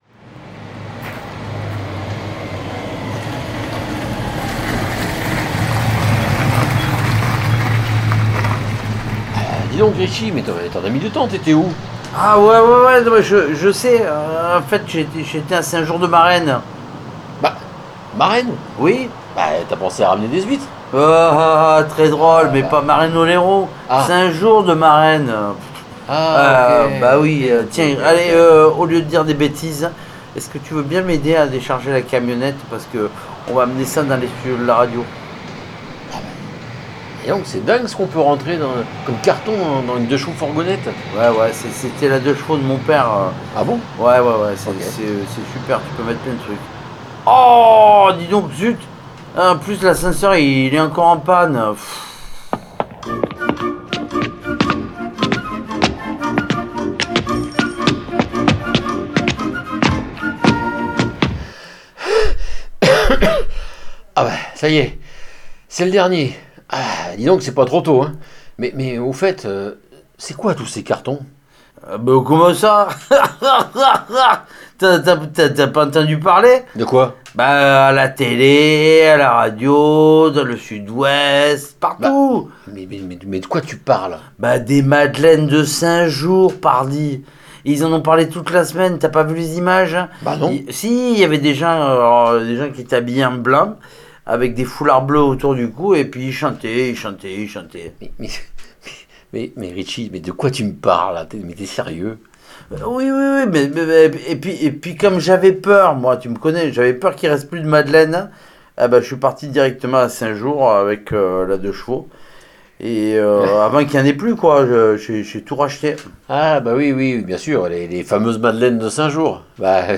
Tout çà dans la bonne humeur coutumière et le Rock’n roll (quoi qu’il arrive)